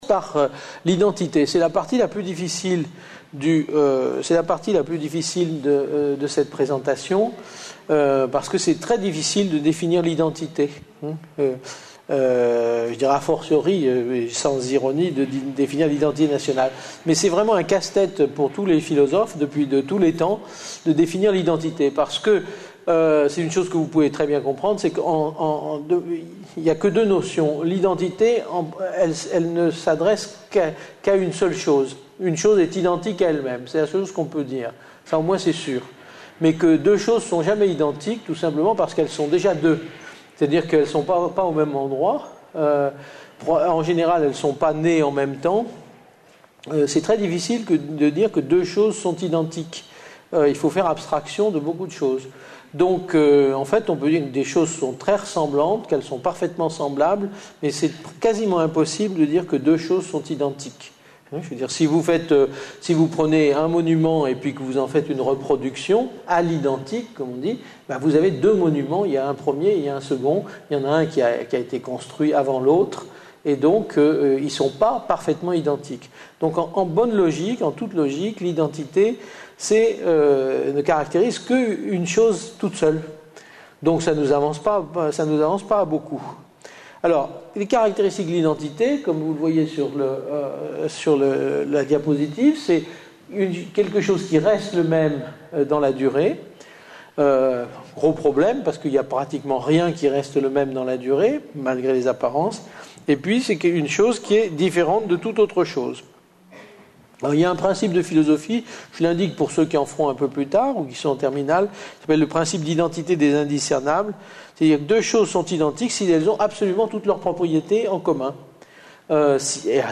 Une conférence de l'UTLS au Lycée Identité et communautarisme par Yves Michaud Lycée Henri Matisse (Montreuil 93)